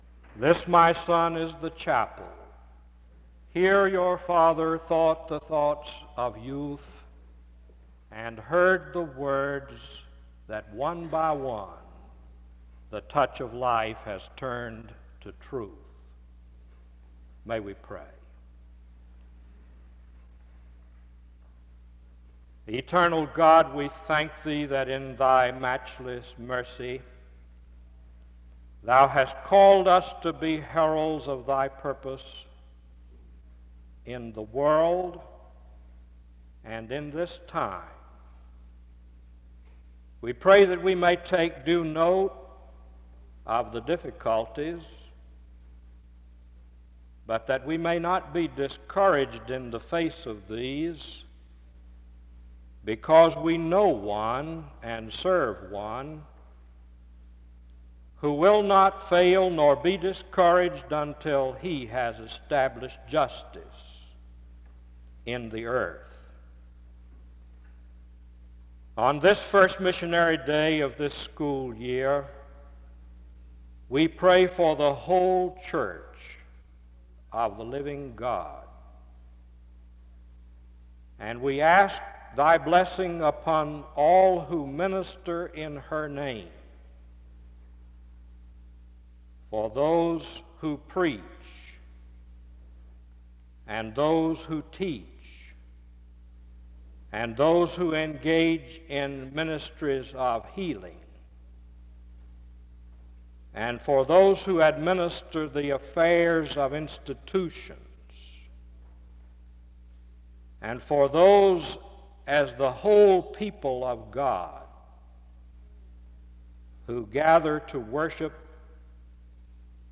Wake Forest (N.C.)
SEBTS Chapel and Special Event Recordings